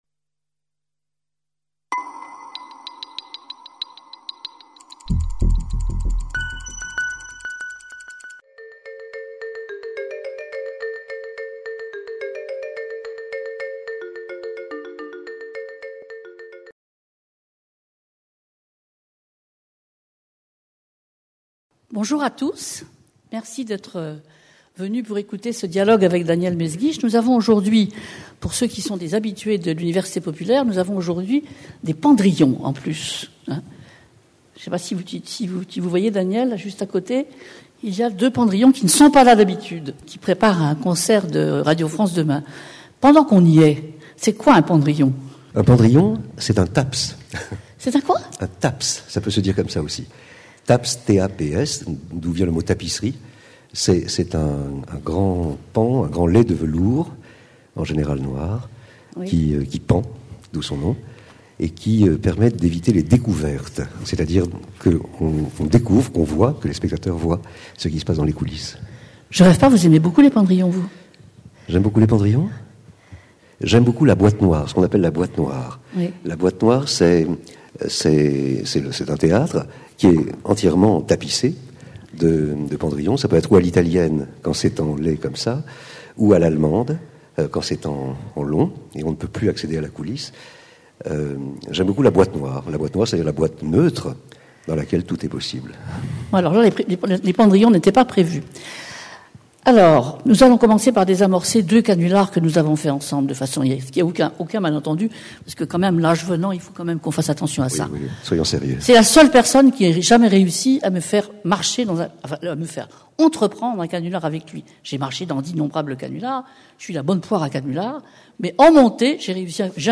Conférence de l’Université populaire du quai Branly (UPQB), donnée le 4 mai 2012. Rencontre animée par Catherine Clément.